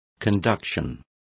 Complete with pronunciation of the translation of conduction.